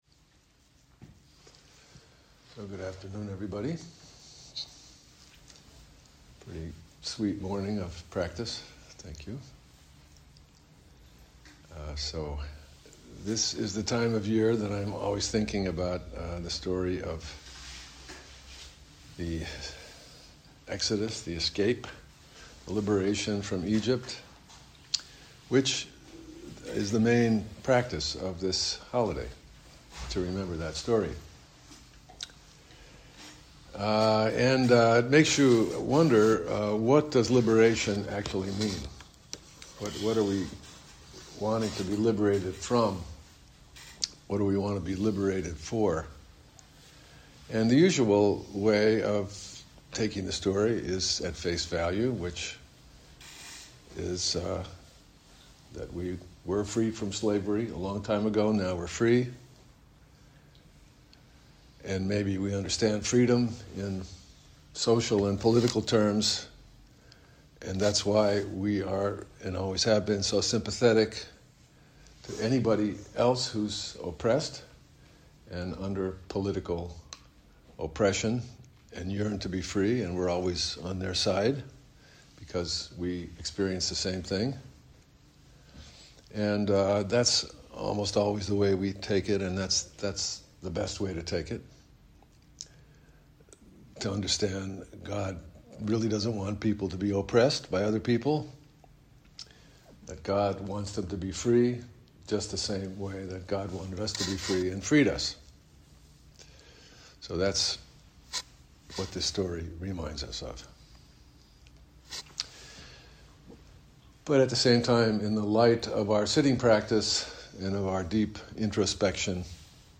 gives a talk on Passover to the Makor Or group at Congregation Beth Shalom